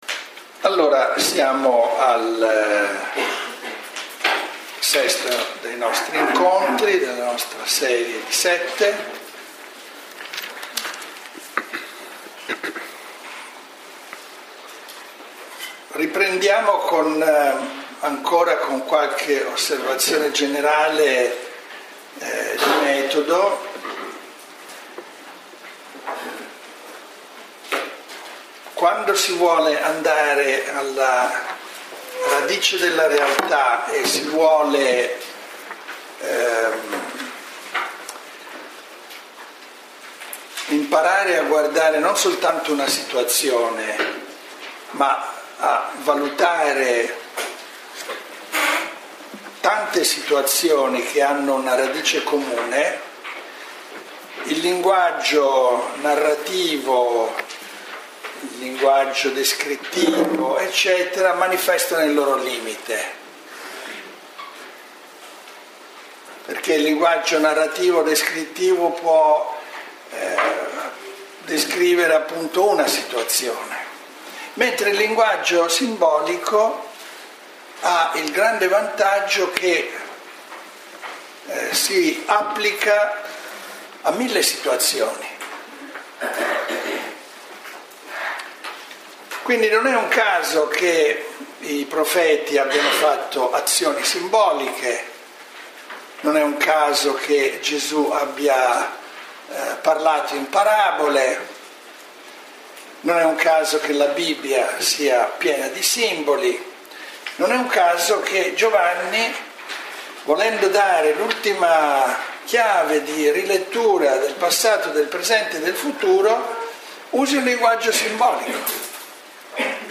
Lectio 6 – 16 marzo 2014 – Antonianum – Padova